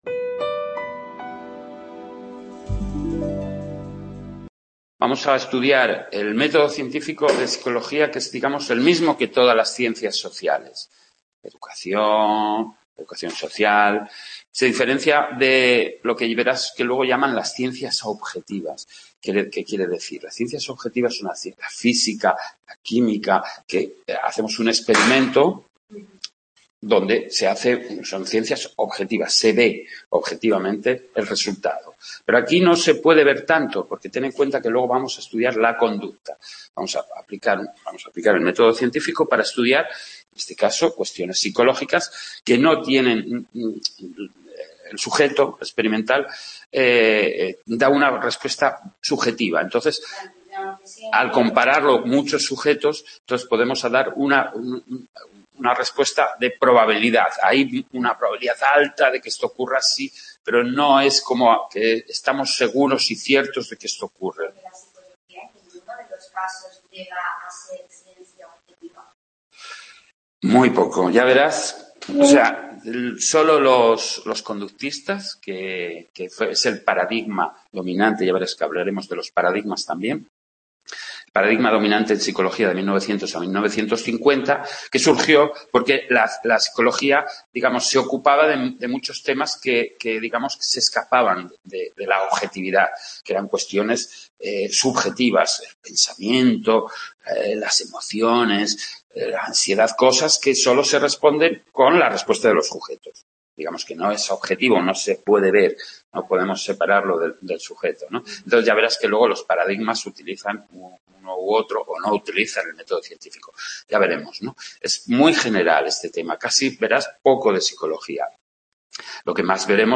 Tutoría del tema 4 de Psicologia